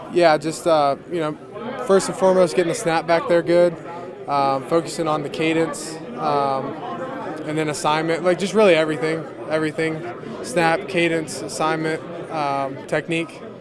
Second-round pick Zach Frazier is competing for the starting job at center and he said he’s learning a lot in his first few weeks of camp.